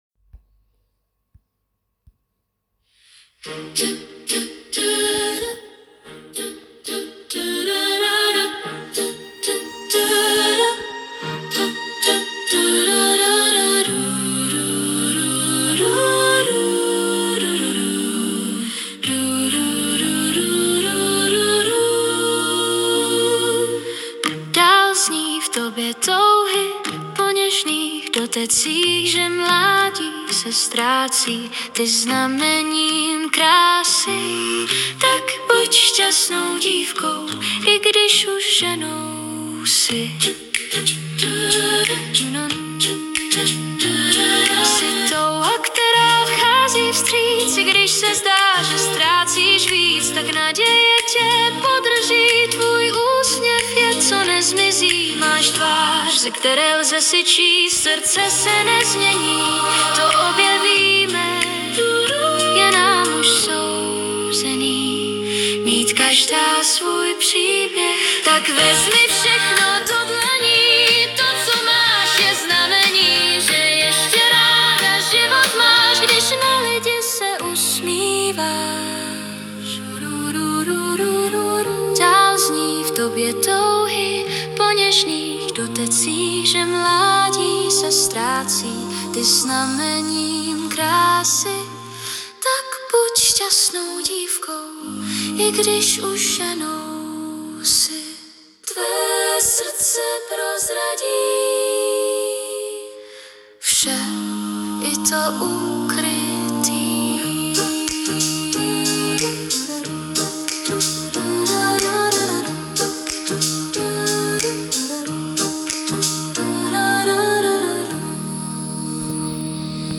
Zpěv +zhudebnění AI